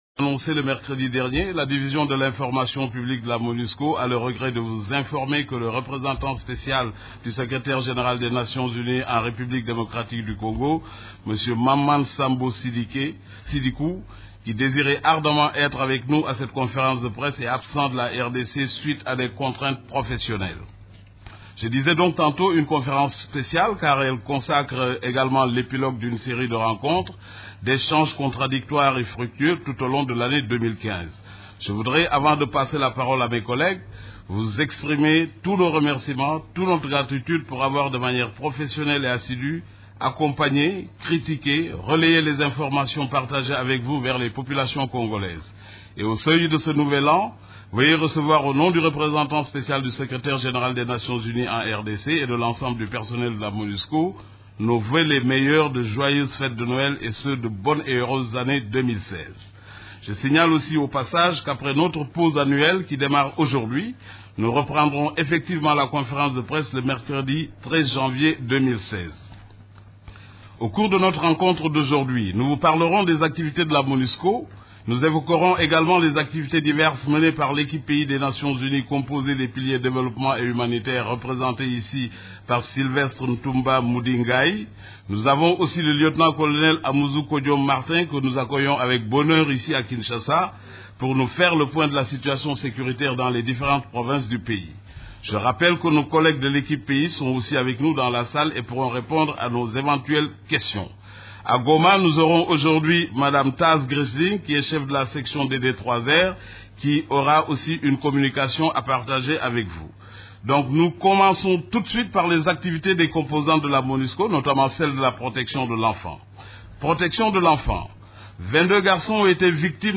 Conférence de presse du 16 décembre 2015
Les activités des composantes de la Monusco et celles des agences et programmes des Nations unies ainsi que de la situation militaire ont été au centre de la conférence de presse hebdomadaire des Nations unies du mercredi 16 décembre à Kinshasa.